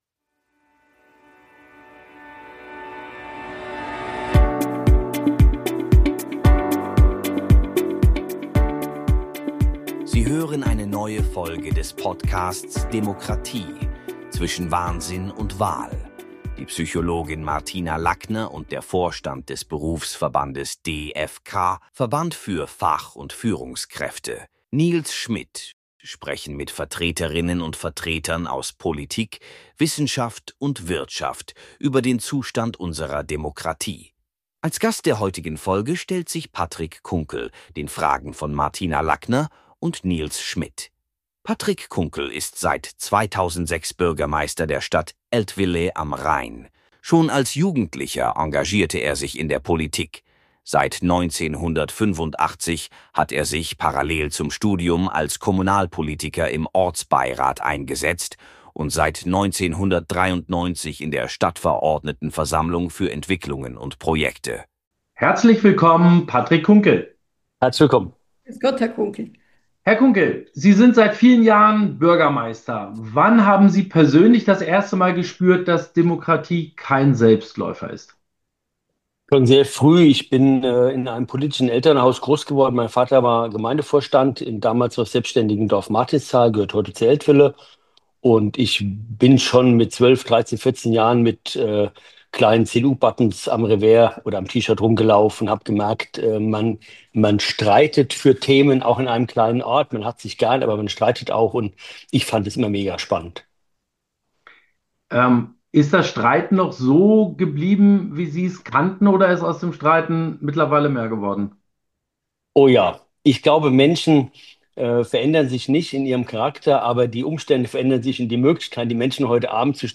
Zu Gast: Patrick Kunkel ist seit 2006 Bürgermeister der Stadt Eltville am Rhein.
Ein spannendes Gespräch mit direkten Einblicken in die Erfahrungen und Abläufe in die Kommunalpolitik.